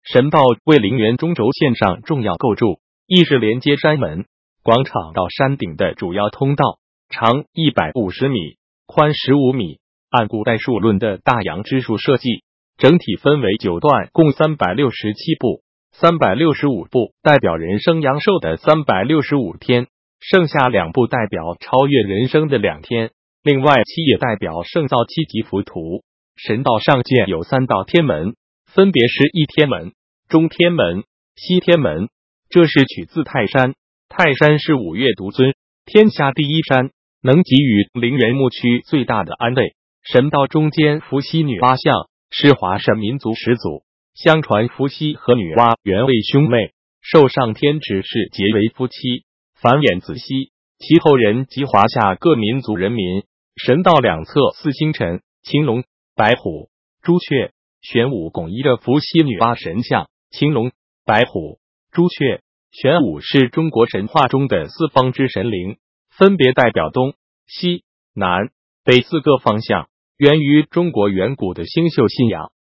语音讲解：